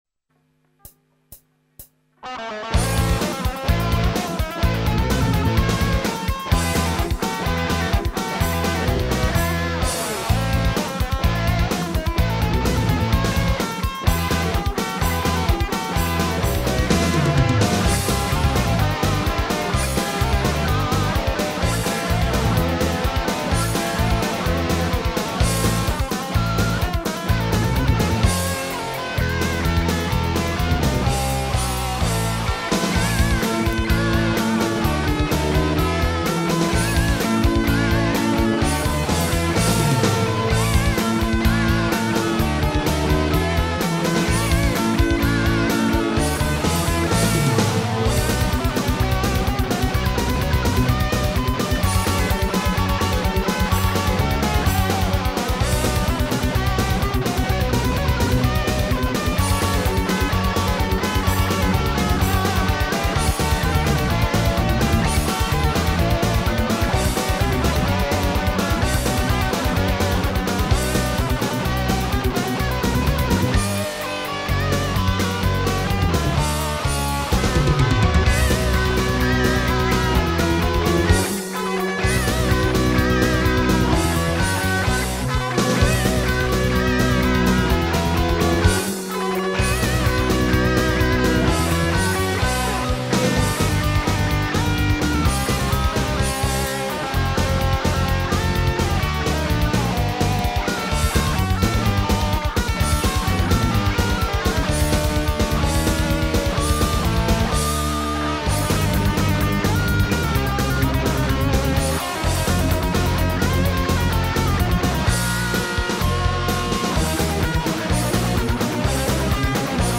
- A la guitare lead
Elles vont du rock au métal avec un soupçon de classique le tout sur des paroles tirées des "Fleurs du mal" de Beaudelaire et de légendes bretonnes.
Démo musicale
sur un quatre pistes avec une boîte à rythme